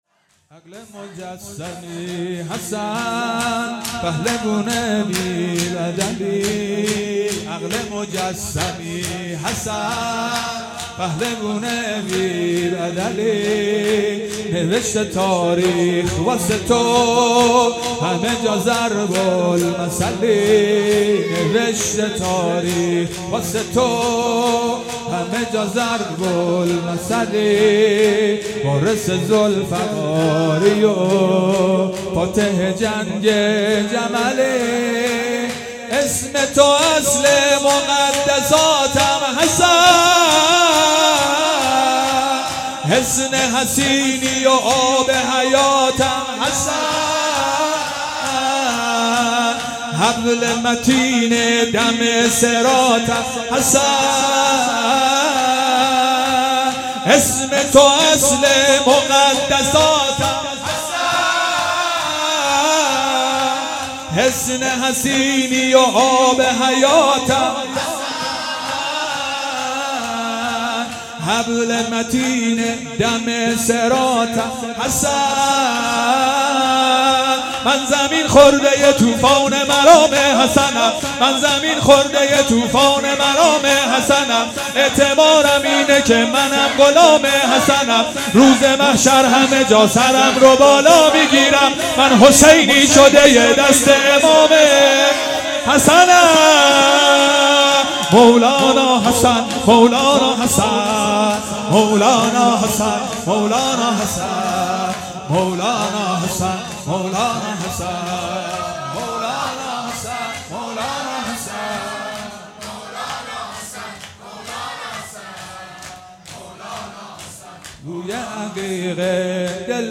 شب پنجم محرم الحرام 1441